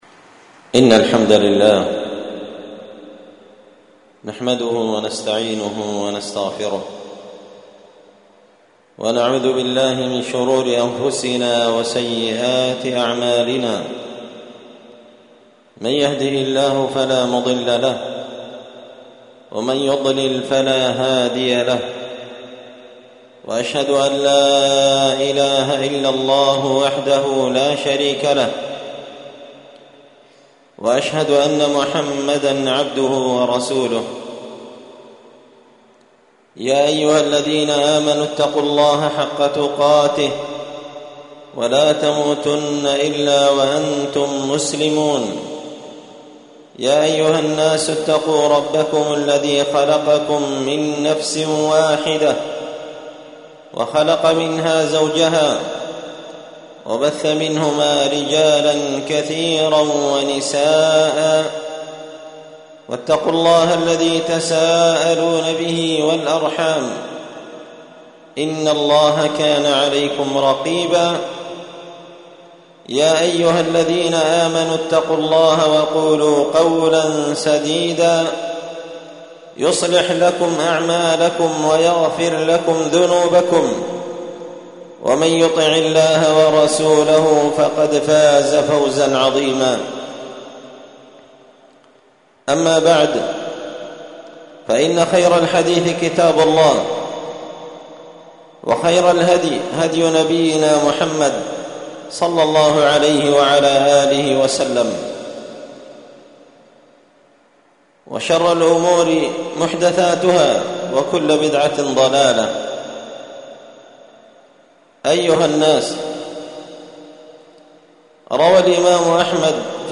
خطبة جمعة بعنوان: